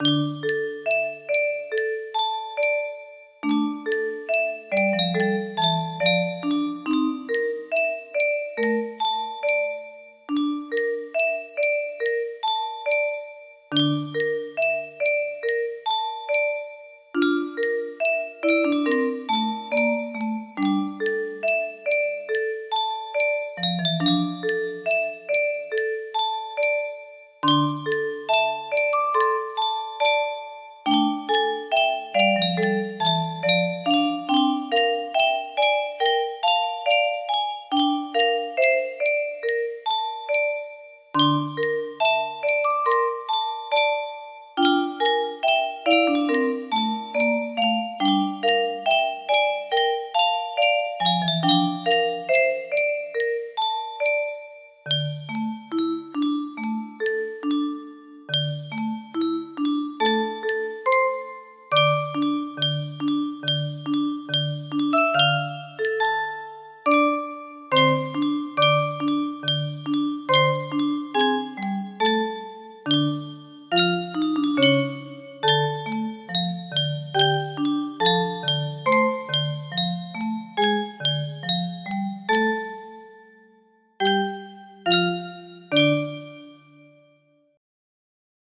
pizza party music box melody
Full range 60